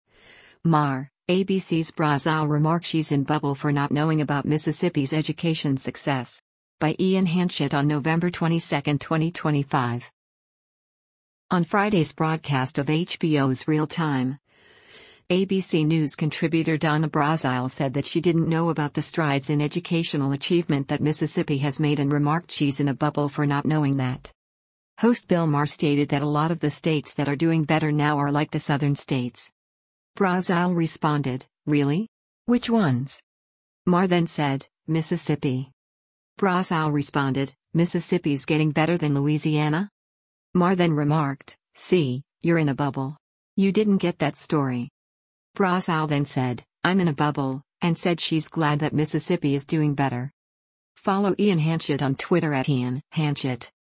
On Friday’s broadcast of HBO’s “Real Time,” ABC News Contributor Donna Brazile said that she didn’t know about the strides in educational achievement that Mississippi has made and remarked she’s “in a bubble” for not knowing that.
Host Bill Maher stated that “a lot of the states that are doing better now are like the southern states.”